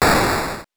8 bits Elements / explosion
explosion_8.wav